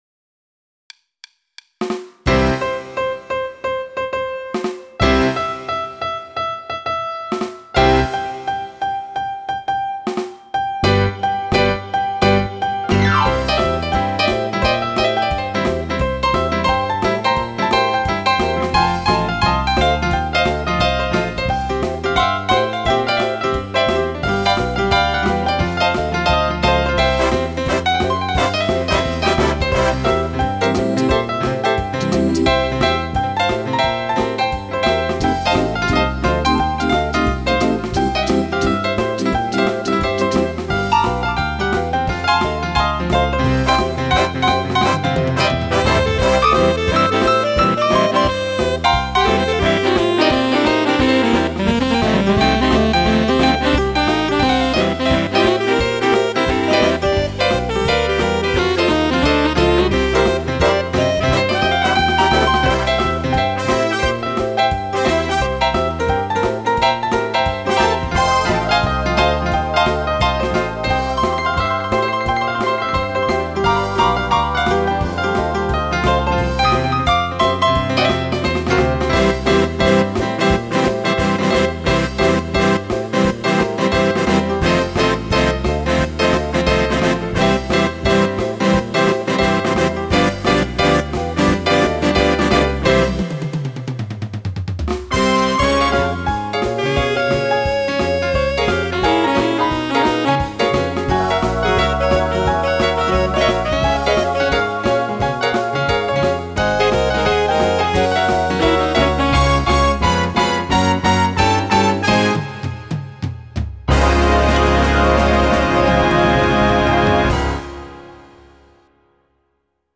4/  Rock and roll